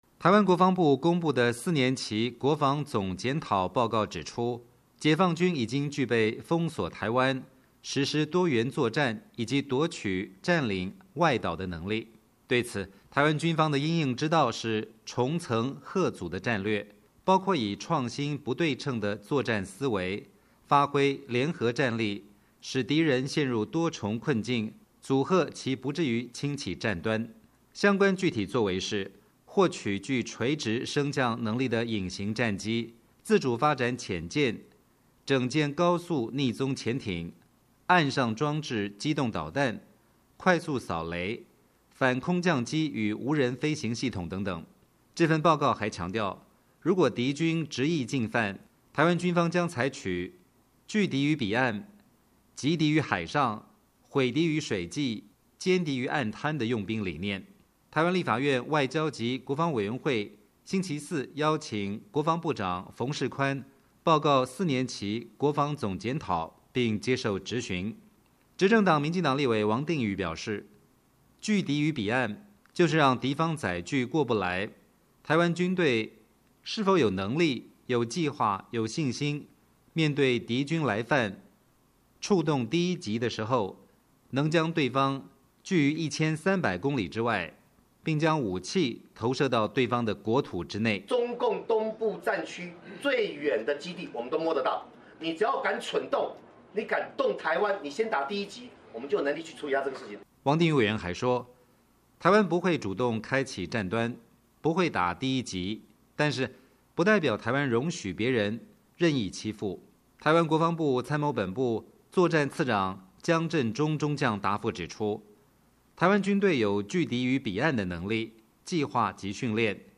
VOA连线：台军方提战略新主张，朝野立委质疑落实性